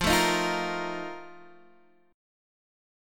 F11 Chord
Listen to F11 strummed